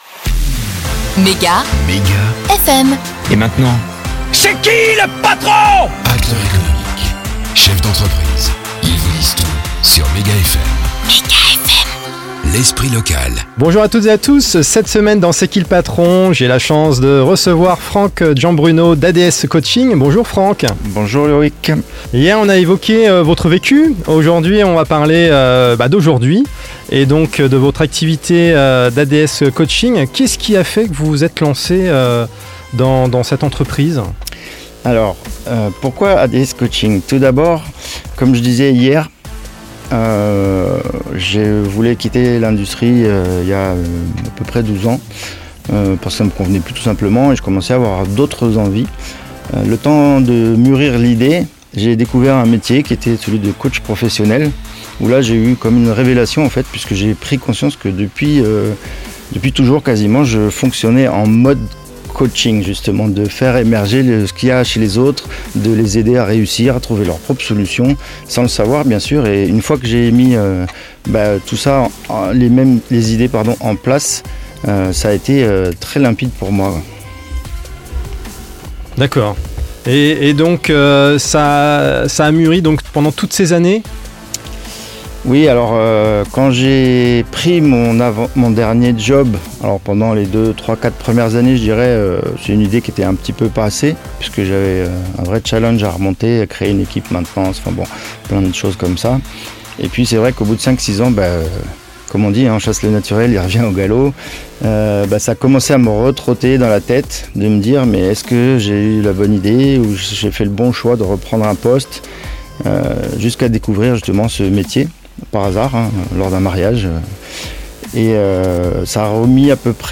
Si vous avez raté les interviews de ADS-COACHING sur MEGA FM,